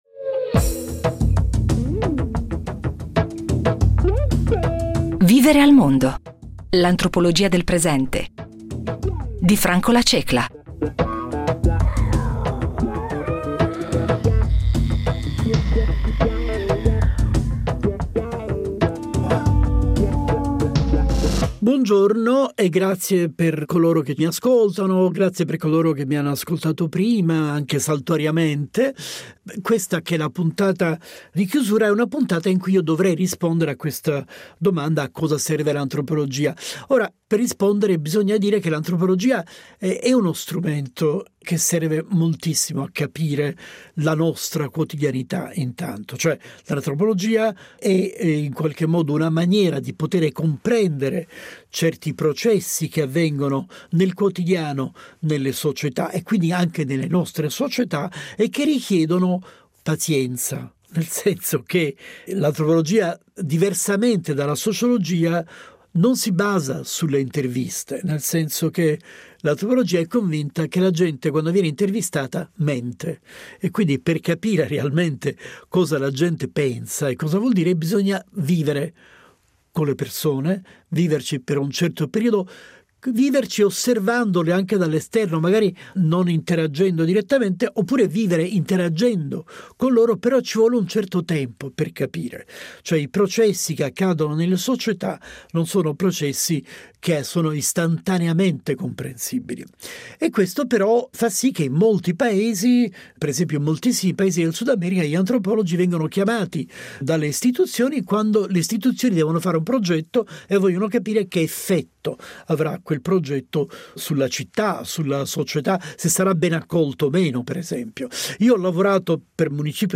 L’antropologia del quotidiano è un approccio che si concentra sullo studio della vita di tutti i giorni, analizzando come le persone vivono, interagiscono e danno significato al mondo che le circonda. Ci accompagna alla sua scoperta Franco La Cecla, antropologo di fama mondiale, che ha pubblicato più di 30 saggi, tradotti in molte lingue.